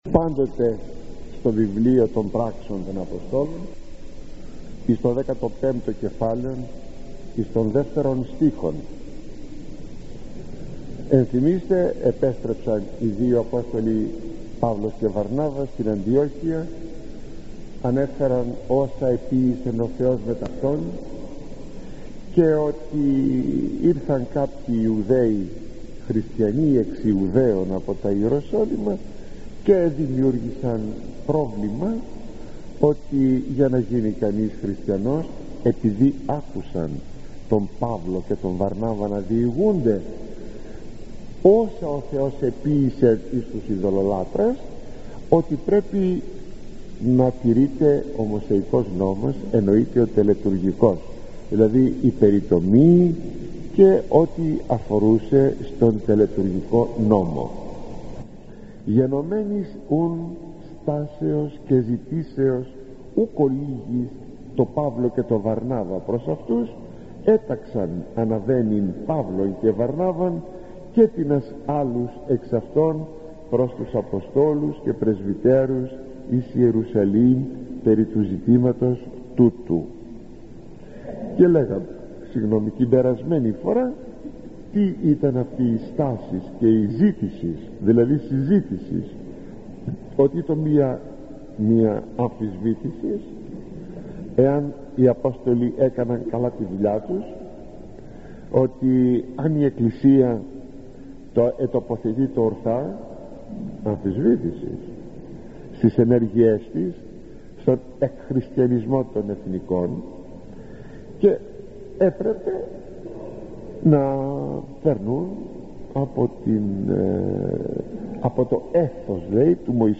Απόσπασμα απομαγνητοφωνημένης ομιλίας του μακαριστού γέροντος στις Πράξεις των Αποστόλων [εκφωνήθηκε στον Ιερό Ναό Αγίου Χαραλάμπους Λαρίσης...